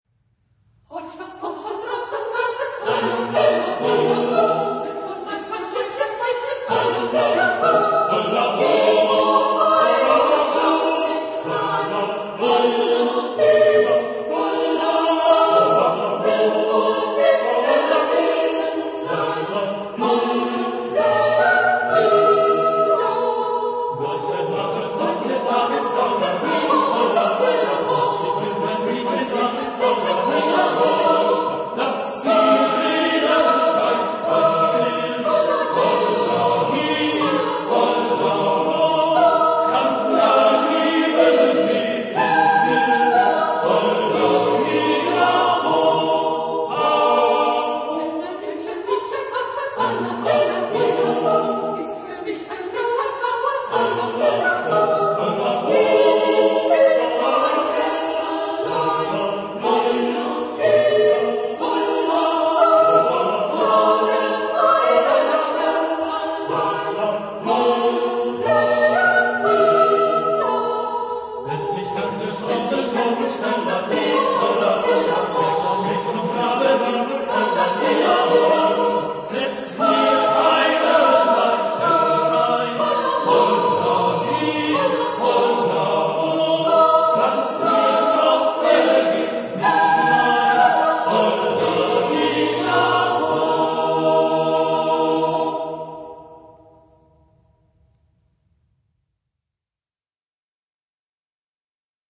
Studentenlied aus der Badischen Pfalz ...
Genre-Style-Form: Folk music ; Partsong ; Secular
Type of Choir: SSATB  (5 mixed voices )
Tonality: E flat major